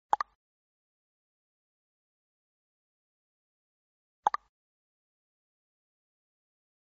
Son du message de Vk